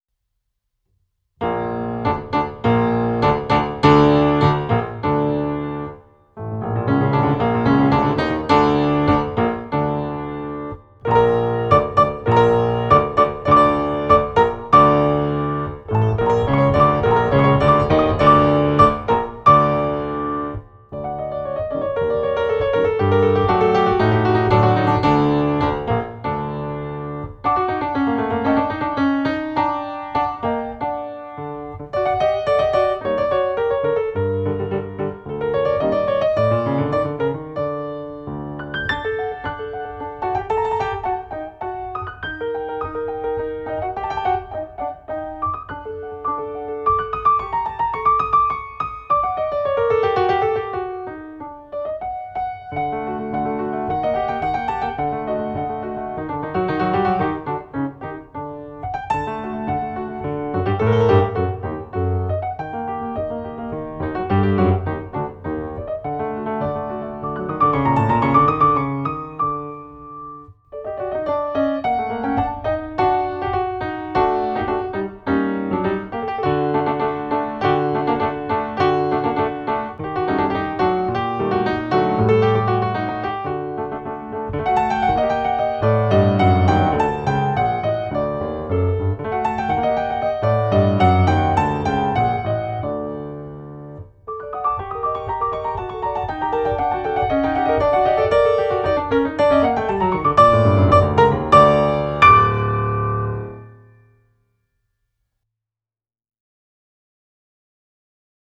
Recorded in 2005, USA.